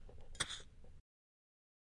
虎头蛇尾被放出水声
描述：香烟被放在水中。
Tag: OWI 放出 熄灭 香烟